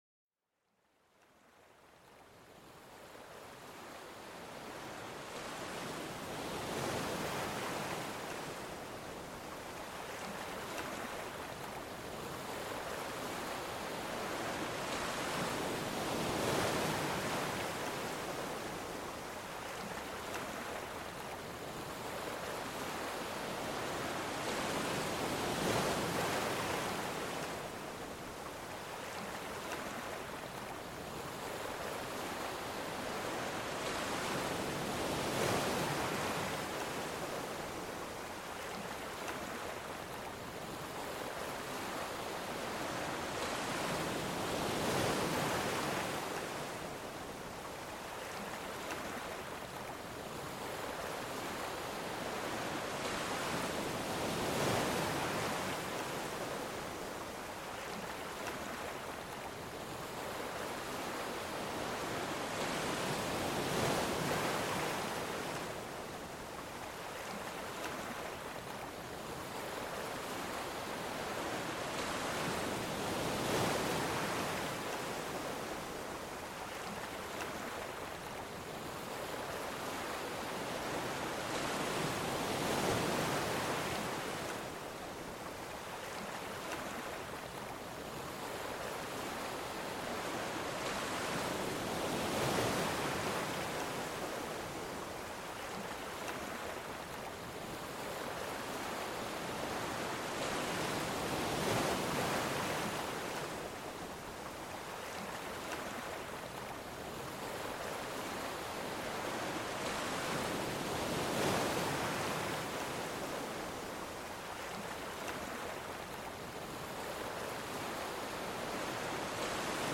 Sumérgete en la inmensidad azul con este episodio dedicado al sonido cautivador de las olas del océano. Déjate arrullar por el ritmo natural y calmante de las olas, una verdadera sinfonía marina que promete relajación y serenidad.